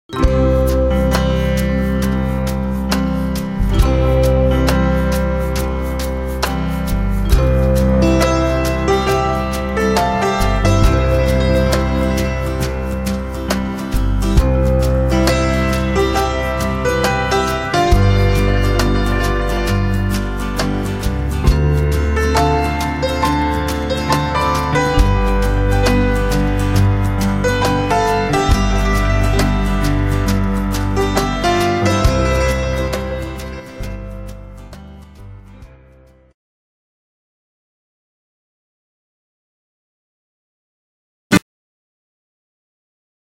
20 CLASSIC PIANO INSTRUMENTALS